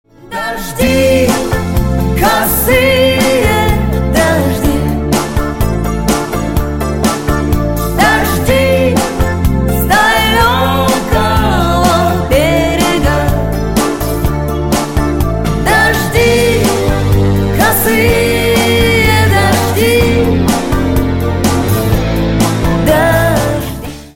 Спокойные И Тихие Рингтоны
Поп Рингтоны